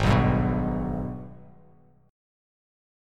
G#mM7 chord